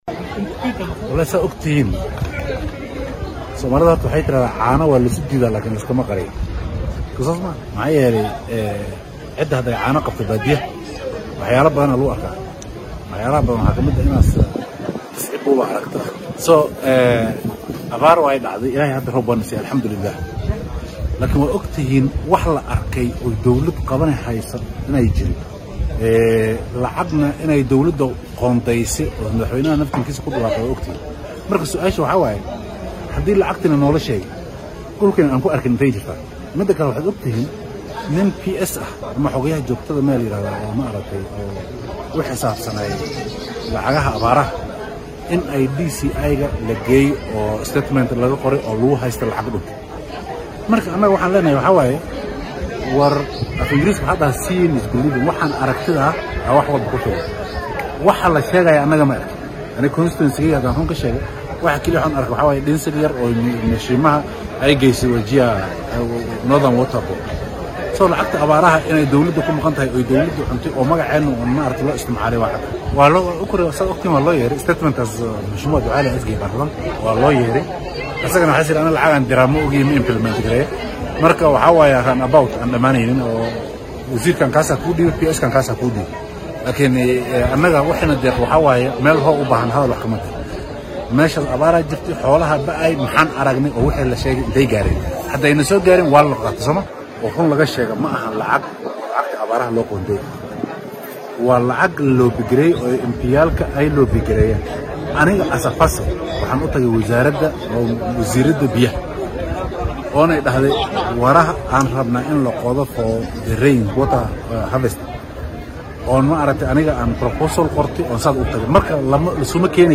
DHAGEYSO:Xildhibaanka galbeedka Wajeer oo ka hadlay lacagaha loo qoondeeyay abaaraha